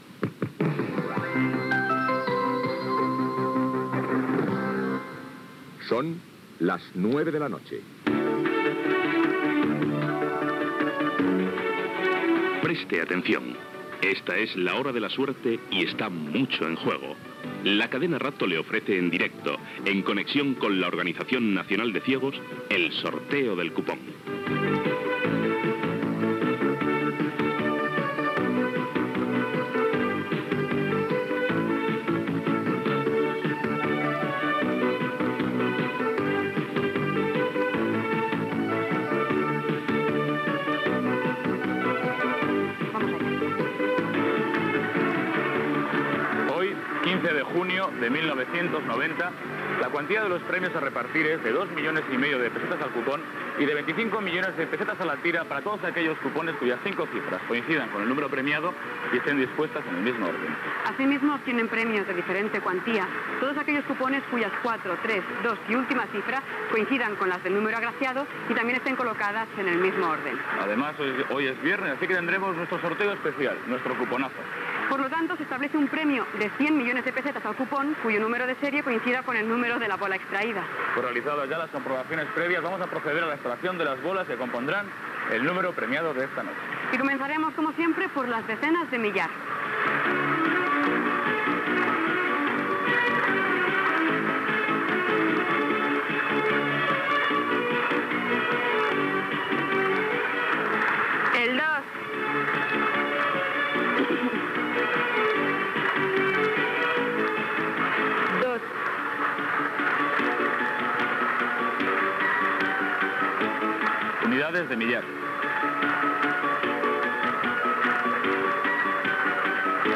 Sintonia de la cadena, careta del programa, premis que es sortejaran, número premiat i localitat
Informatiu